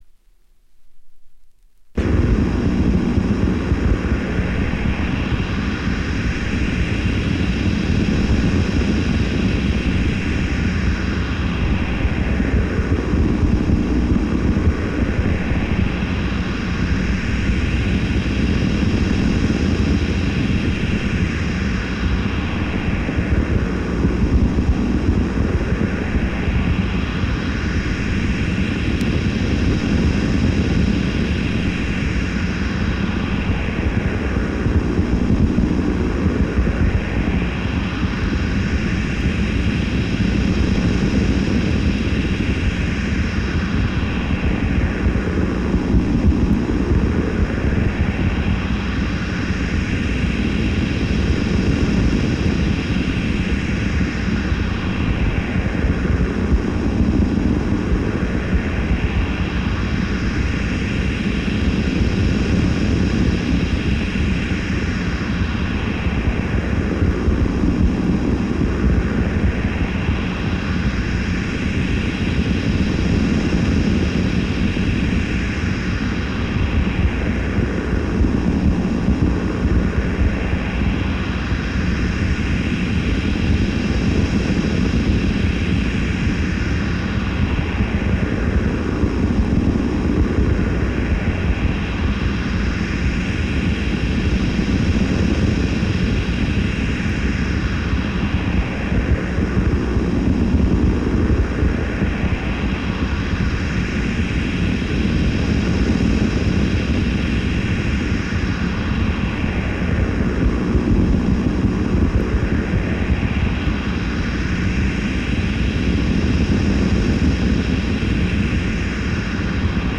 Noise is your consoling bed of roses.